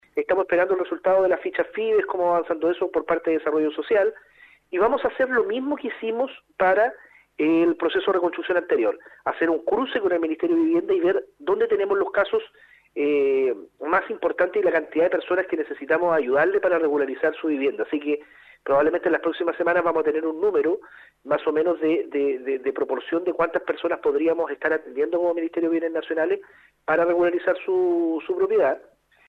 En el marco del proceso de reconstrucción luego de los incendios forestales y las inundaciones, el Seremi de Bienes Nacionales Eduardo Pacheco, en entrevista con Nuestra Pauta, destacó que ampliaron el plazo que permite a las personas afectadas regularizar sus títulos de dominio.